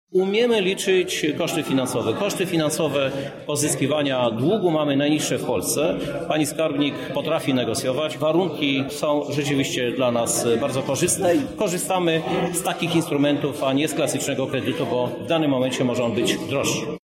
To naturalny proces i podobnie postępuje rząd Mateusza Morawieckiego – mówi prezydent Lublina, Krzysztof Żuk